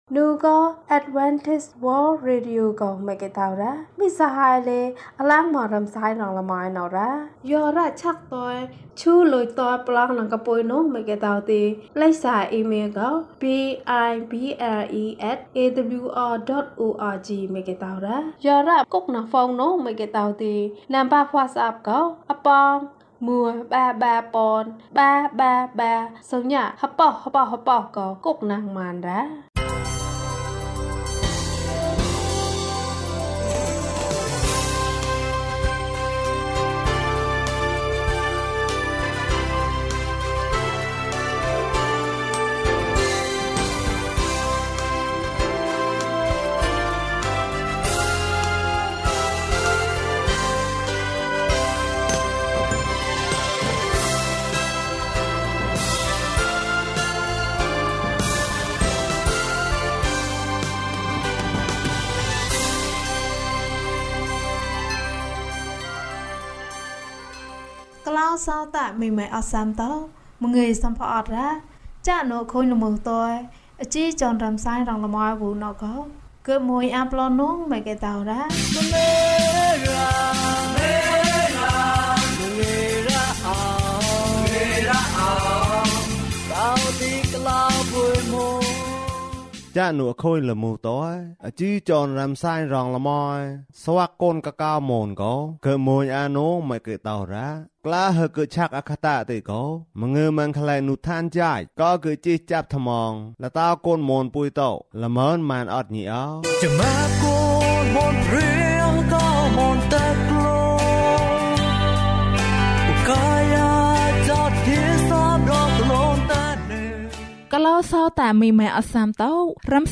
ယေရှု၏ဇာတ်လမ်း။ ကျန်းမာခြင်းအကြောင်းအရာ။ ဓမ္မသီချင်း။ တရားဒေသနာ။